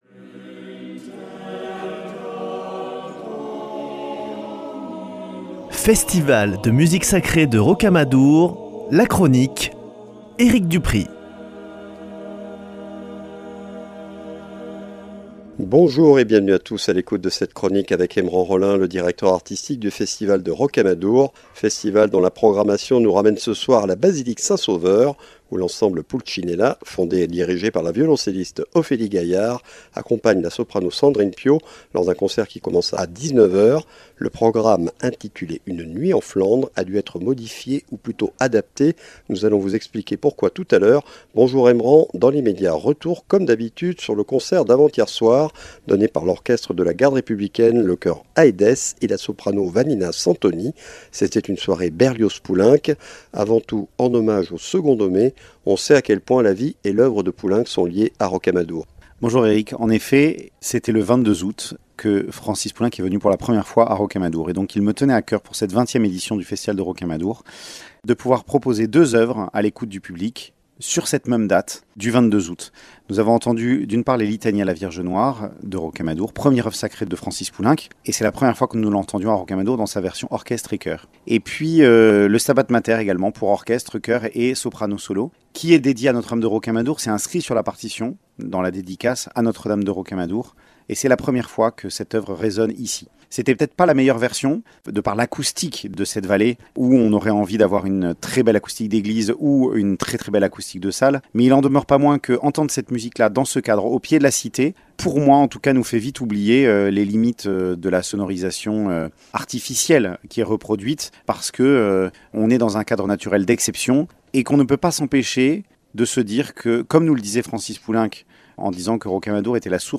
Chronique Festival de Rocamadour : 24 août 2025